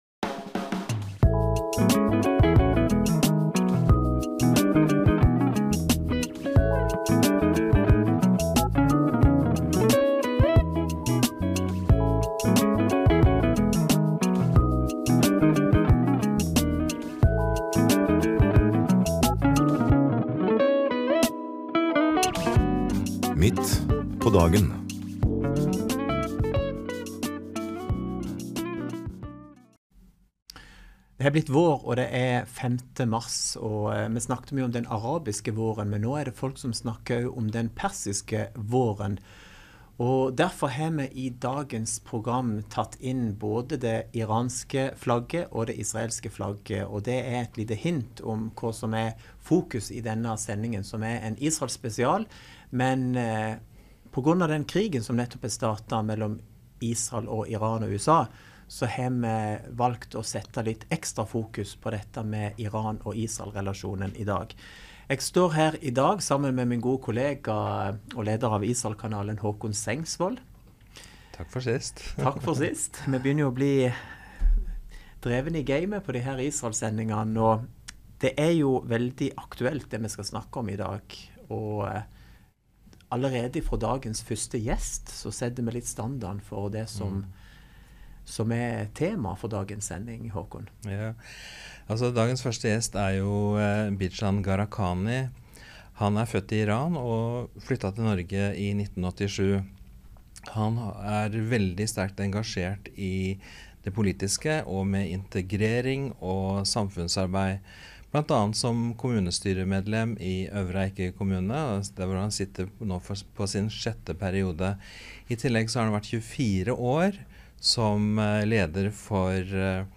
Talkshow